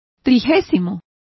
Complete with pronunciation of the translation of thirtieth.